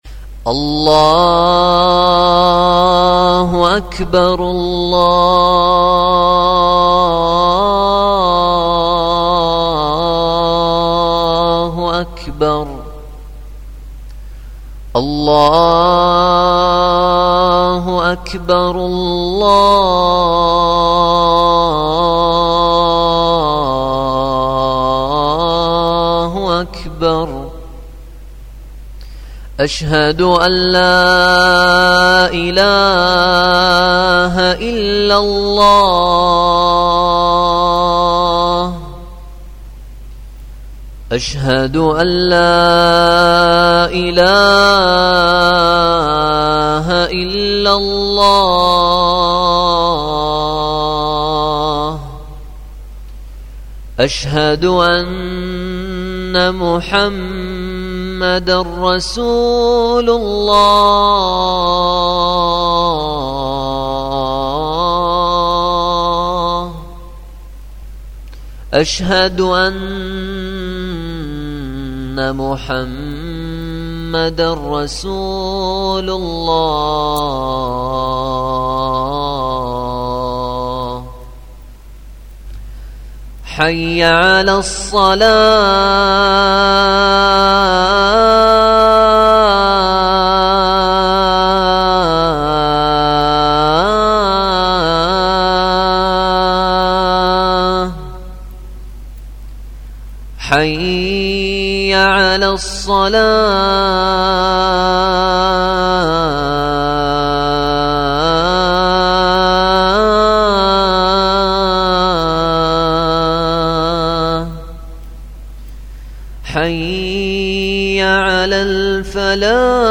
آذان العشاء من بغداد
أناشيد ونغمات